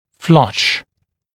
[flʌʃ][флаш]находящийся на одном уровне, заподлицо с чем-л.